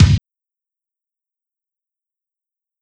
• 1-BassDrum_long.wav is that same file, but I added about 3s of silence to its end
No noise with the 2nd file.
long WAV - no noise
1-BassDrum_long.wav